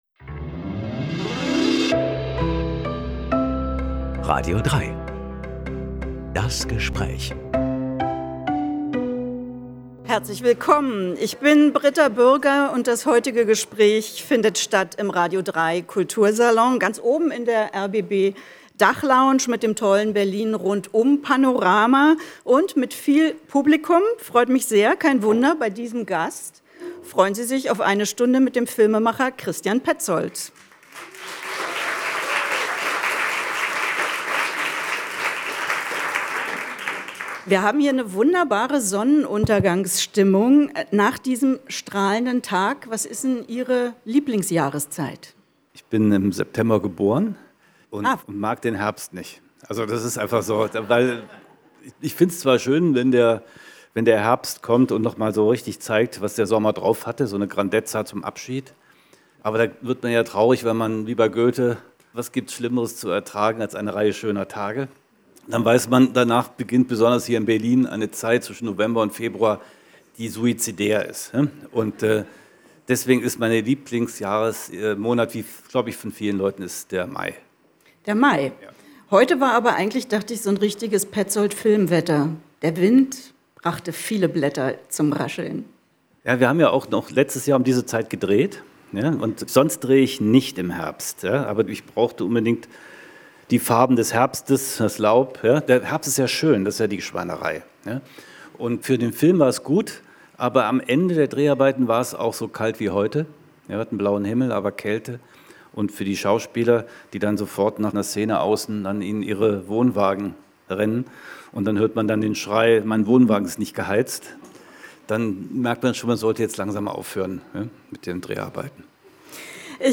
Eine Aufzeichnung des radio3-Kultursalons vom 24. September 2025.